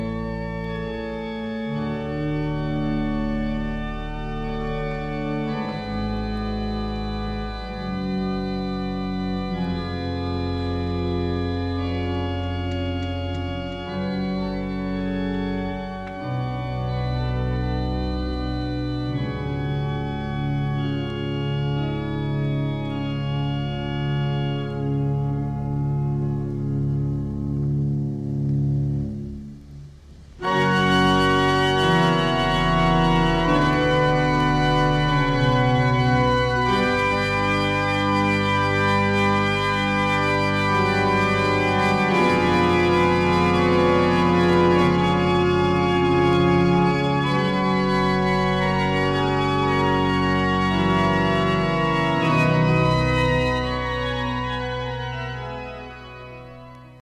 blistering Hammond-heavy score